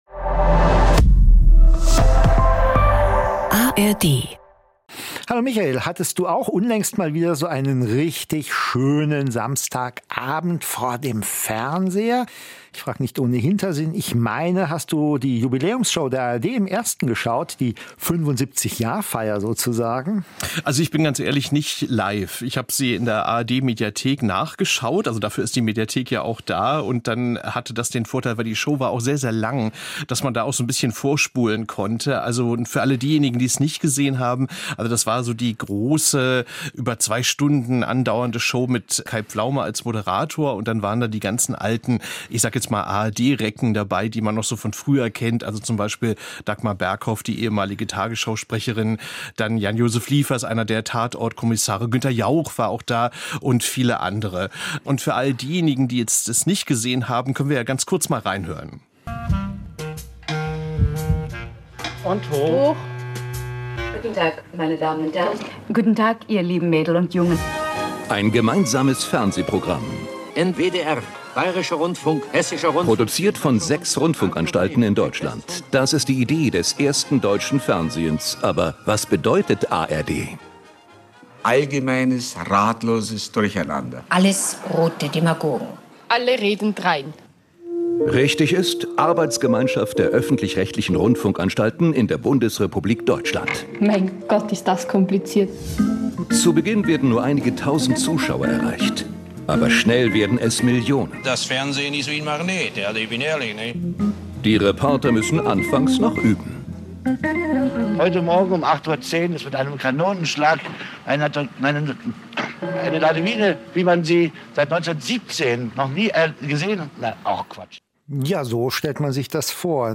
Die Moderatorinnen und Moderatoren informieren, diskutieren und räsonieren jede Woche über neue Trends und kontroverse Themen aus der großen weiten Welt der Medien. Immer zu zweit, immer voller Meinungsfreude und immer auch mit fundierten und gründlich recherchierten Fakten. Gespräche mit Experten und Expertinnen, Umfragen unter Nutzerinnen und Nutzern und Statements aus der Medienbranche können Probleme benennen und Argumente untermauern.